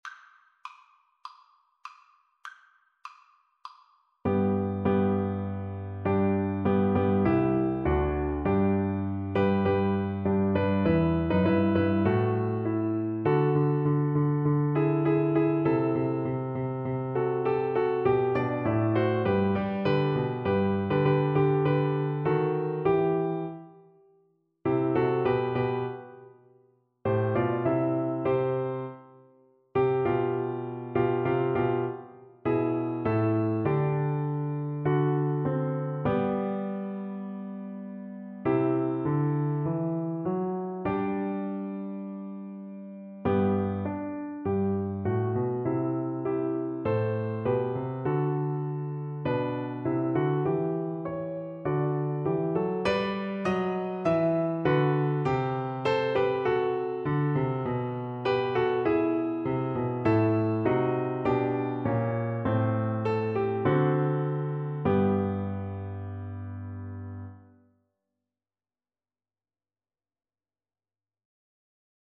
Classical (View more Classical Cello Music)